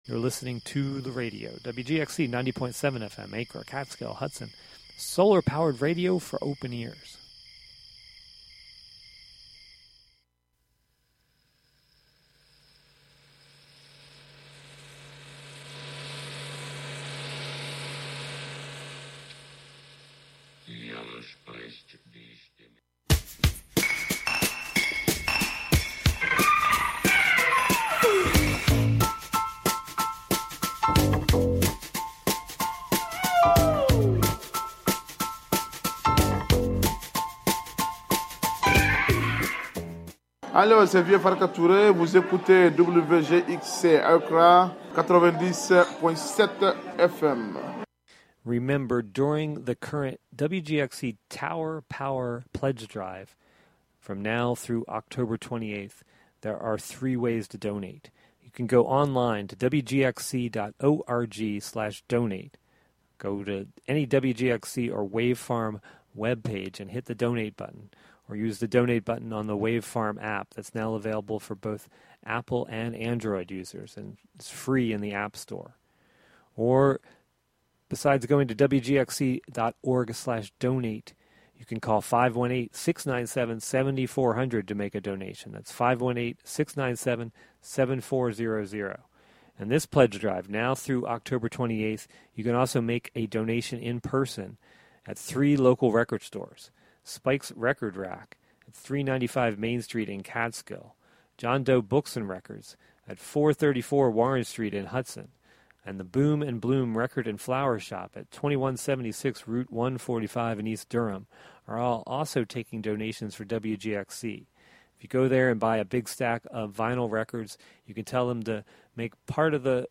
entertainment news and reviews, special features, and the "Forgotten Decades" music mix of lesser-played and/or misremembered songs from the '50s through the '90s.